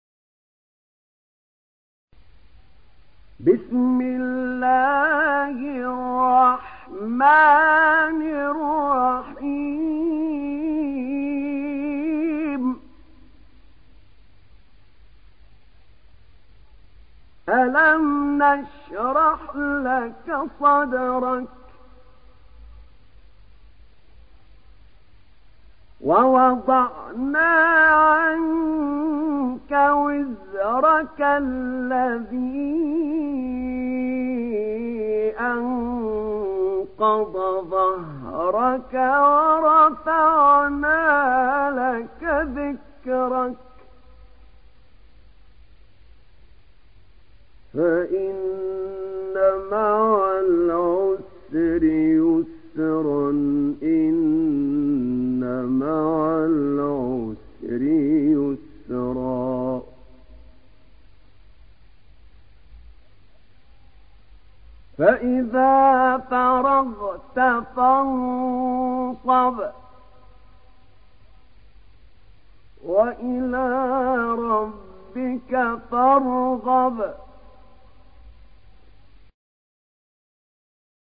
تحميل سورة الشرح mp3 بصوت أحمد نعينع برواية حفص عن عاصم, تحميل استماع القرآن الكريم على الجوال mp3 كاملا بروابط مباشرة وسريعة